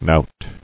Knout (nout ∨ n??t), n. [Russ. knut'; prob. of Scand. origin; cf. Sw. knut knot, knout, Icel. kntr knot: cf. F. knout.